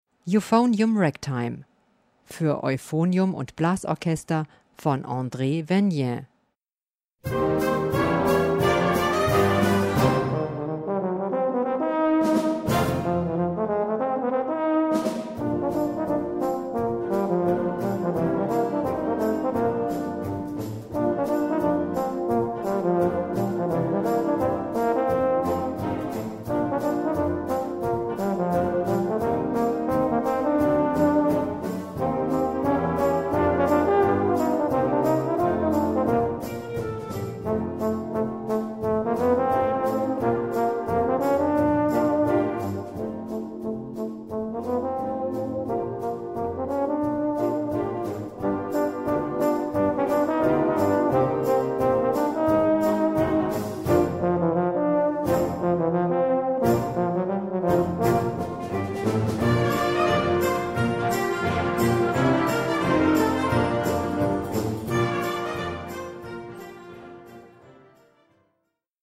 Gattung: Solo für Euphonium und Blasorchester
Besetzung: Blasorchester
in einem Stück im Stil eines schwungvollen Ragtime geschieht